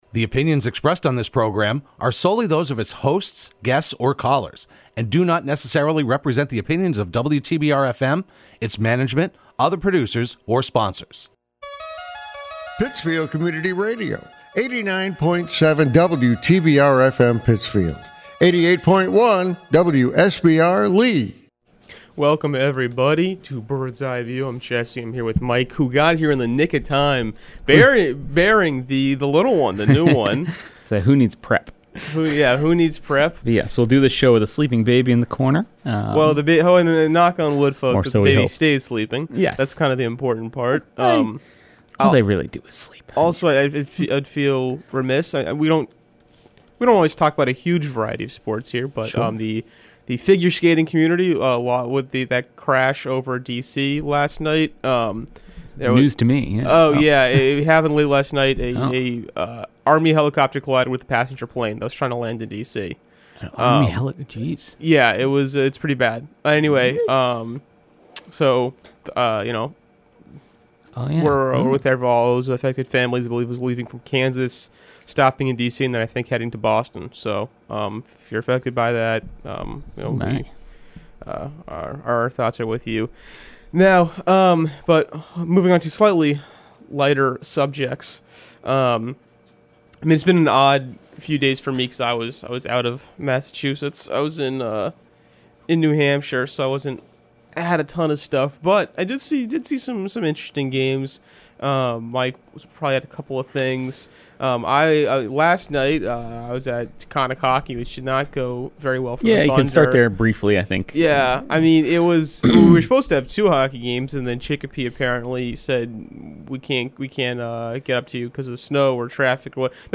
Broadcast live every Thursday morning at 10am on WTBR.